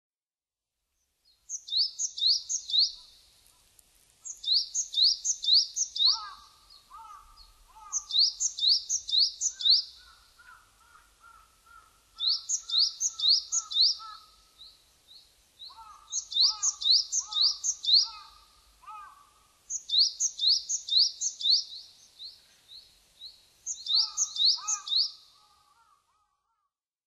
シジュウカラ　Parus majorシジュウカラ科
日光市稲荷川中流　alt=730m  HiFi --------------
Windows Media Audio FILE MPEG Audio Layer3 FILE  Rec.: SONY TC-D5M
Mic.: audio-technica AT822
他の自然音：　 ハシブトガラス